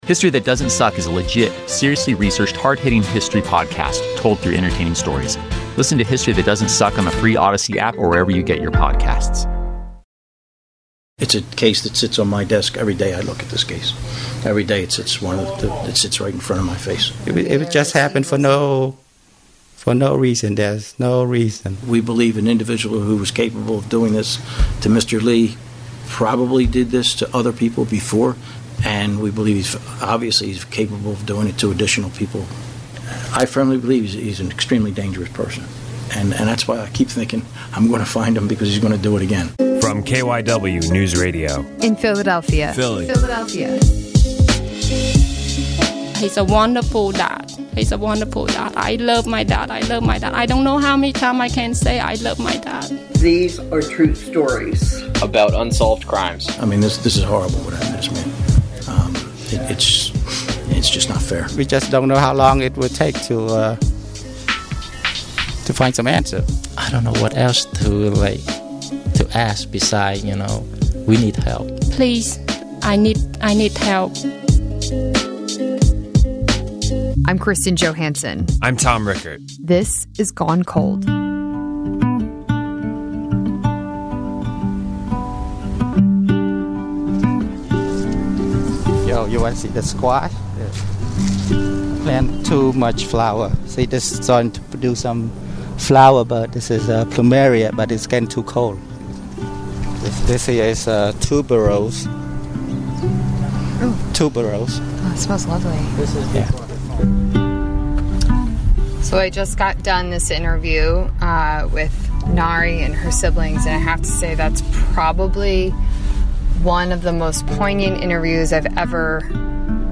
Gone Cold is a true crime podcast about unsolved murders and cold cases in Philadelphia and the Delaware Valley.